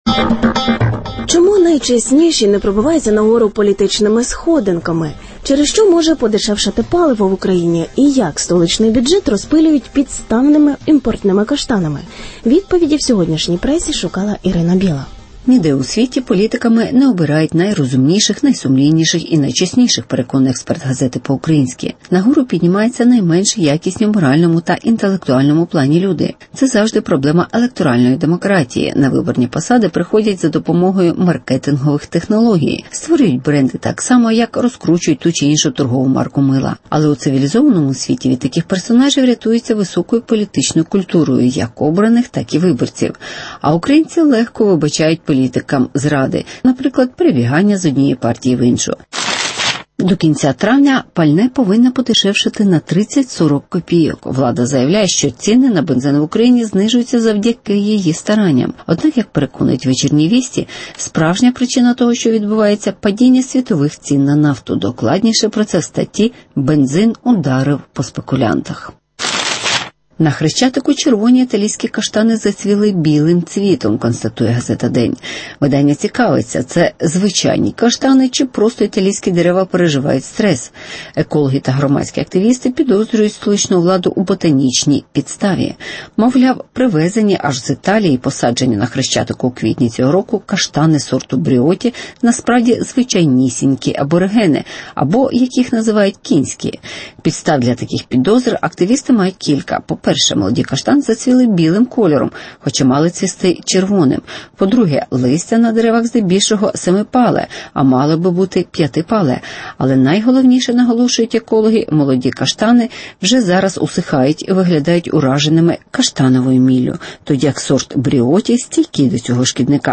Статки українських політиків крадені (огляд преси)